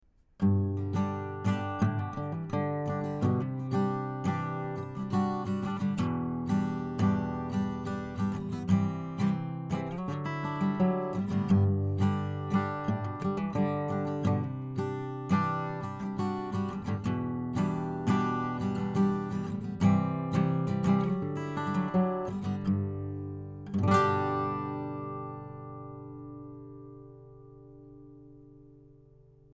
Type: Cardioid condenser
Acoustic GTR Finger Picking & Strumming – 35cm from 12th Fret